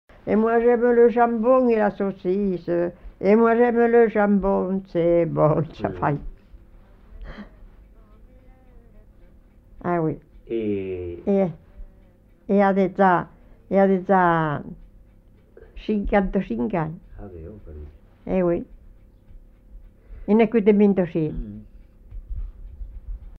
Aire culturelle : Haut-Agenais
Lieu : Fumel
Genre : chant
Effectif : 1
Type de voix : voix de femme
Production du son : chanté